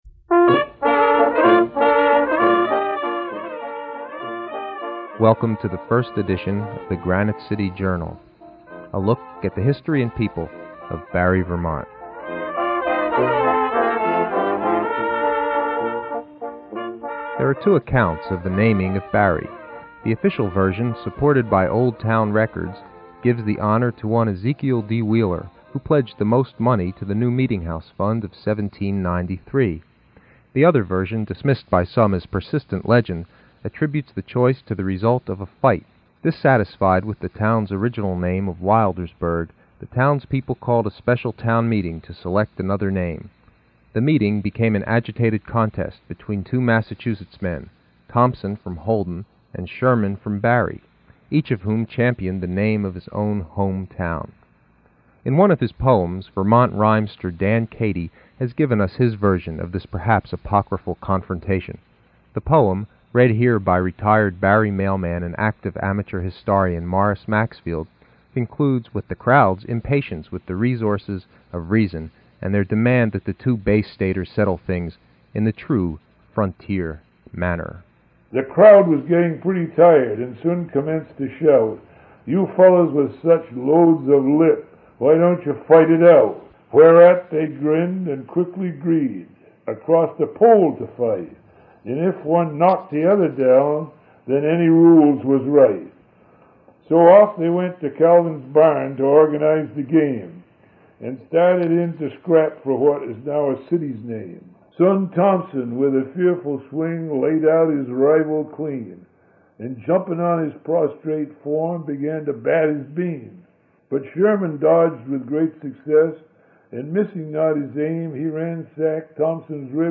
As a result, the audio quality of the interviews varies.